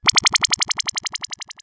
RetroGamesSoundFX